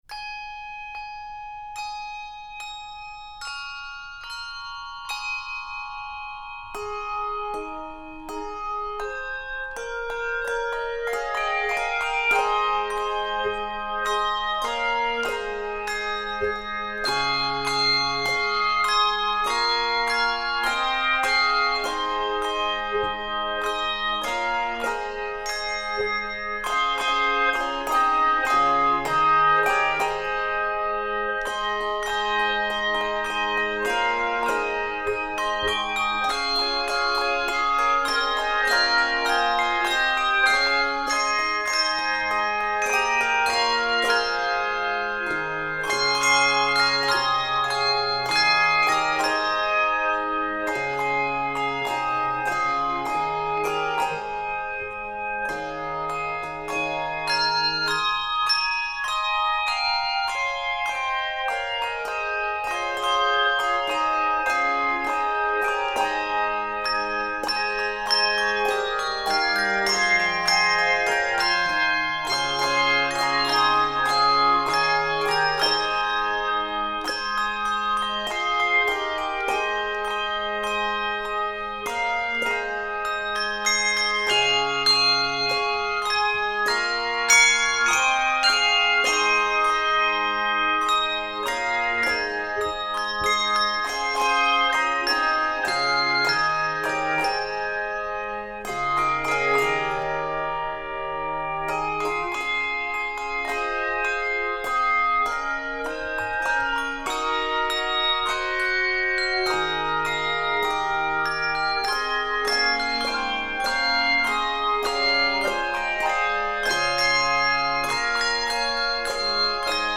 handbells
This lively medley